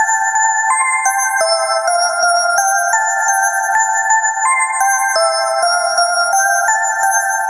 标签： 128 bpm Dance Loops Bells Loops 1.40 MB wav Key : C
声道立体声